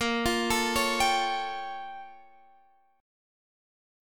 A#mM13 Chord
Listen to A#mM13 strummed